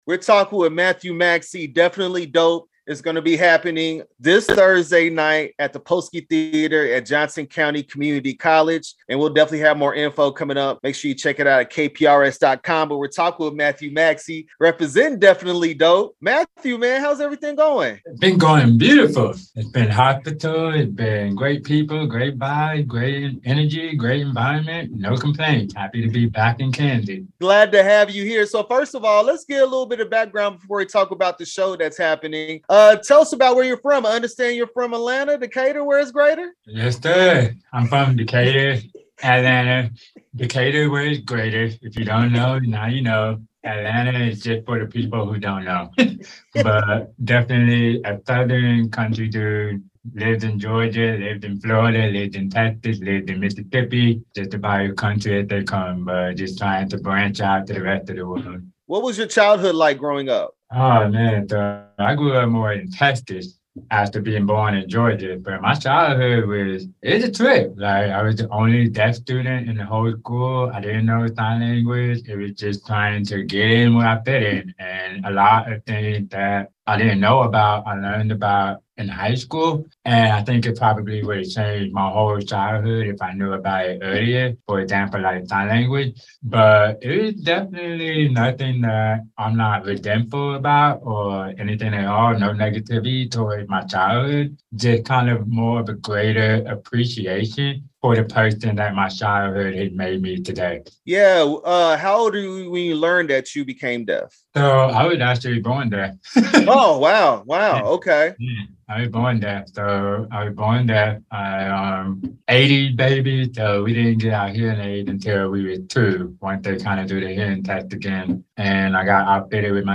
DEAFinitely Dope interview 9/28/22